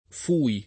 fui [ f 2 i ]